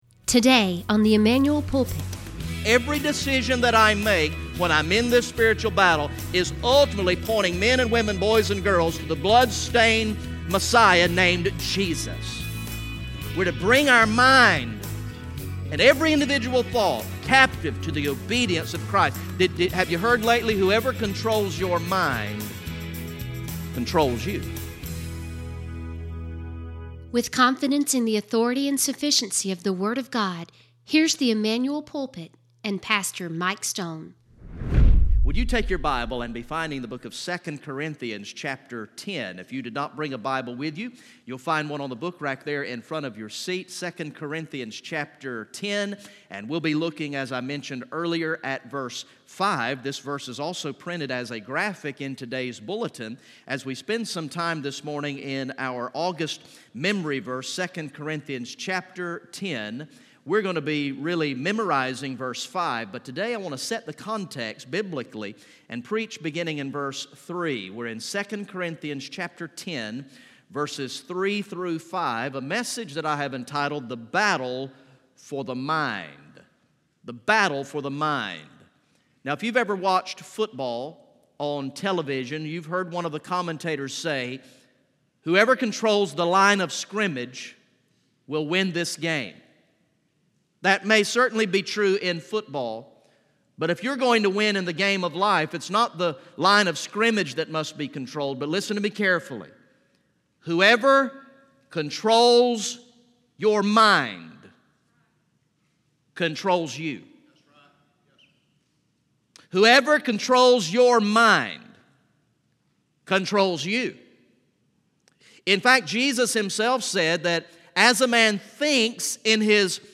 From the morning worship service on Sunday, August 5, 2018